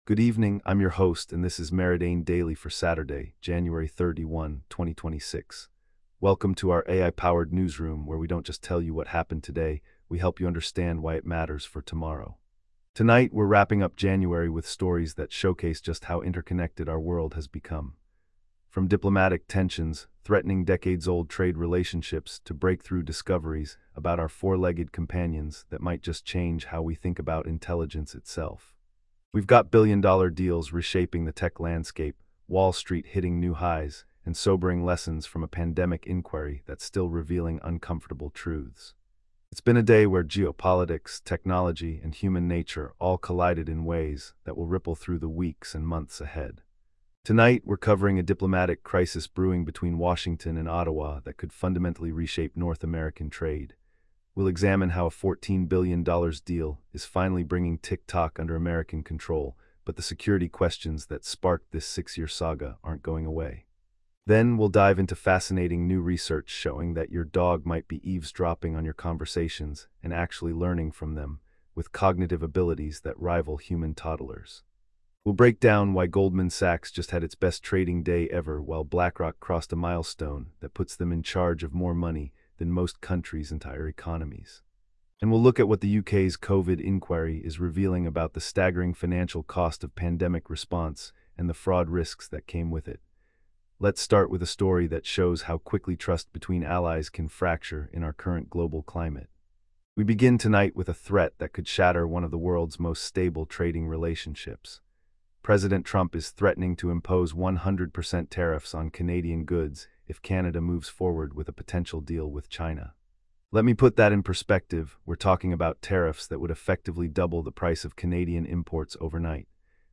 Your nightly AI-powered news briefing for Jan 31, 2026